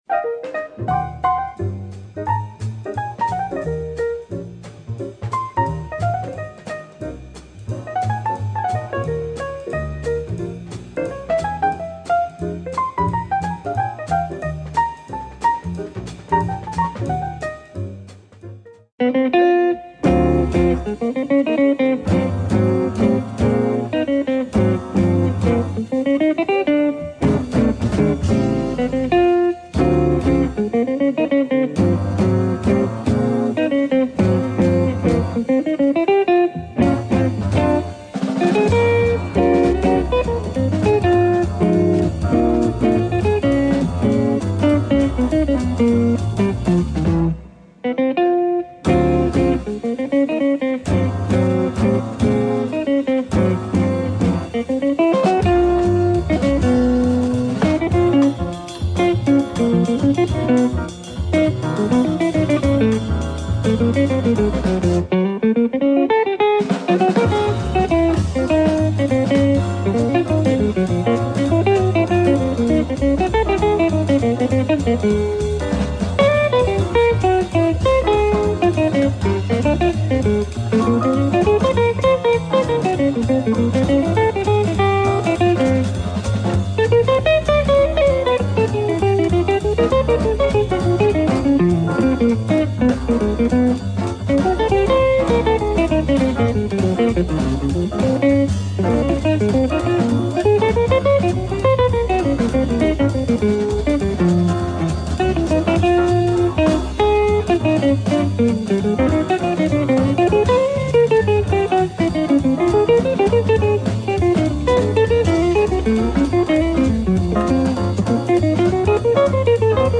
Guitarists
interview
percussionist